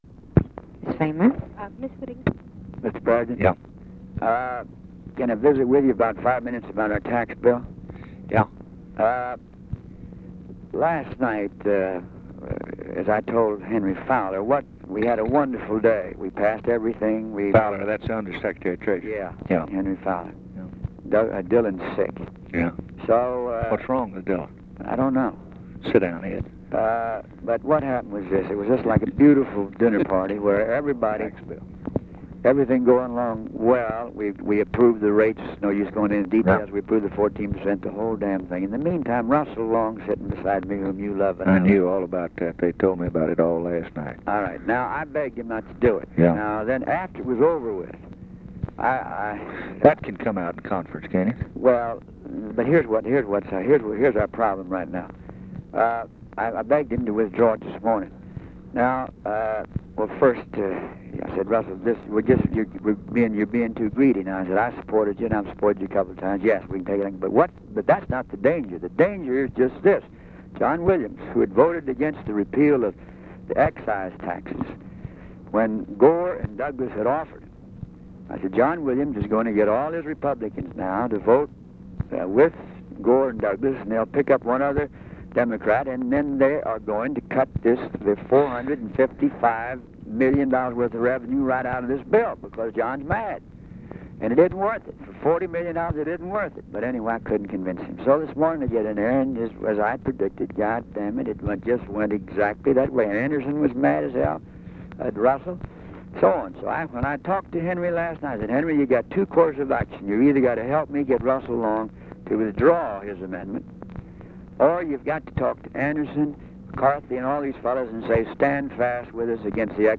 Conversation with GEORGE SMATHERS, January 23, 1964
Secret White House Tapes